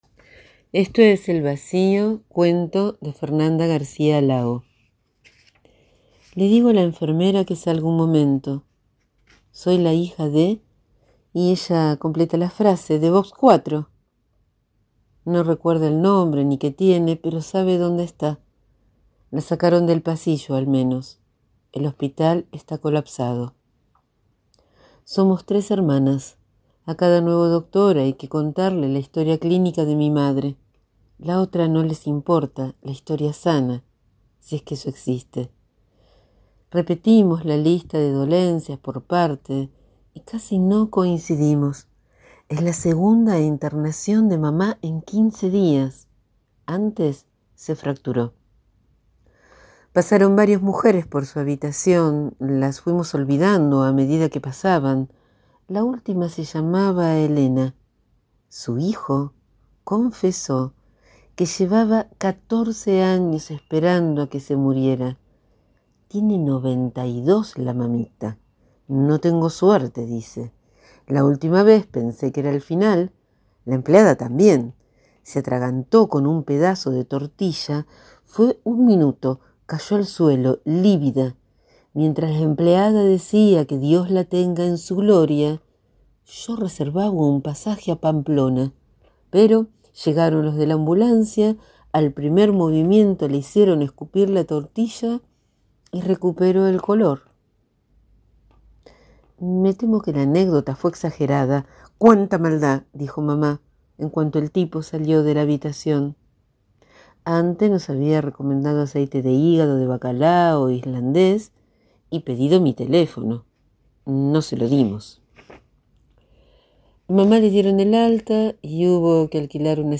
Hoy leo y dejo en audio el relato «Esto es el vacío» de la escritora, poeta y directora escénica Fernanda García Lao (Mendoza, 1966) extraído de su libro «Teoría del tacto»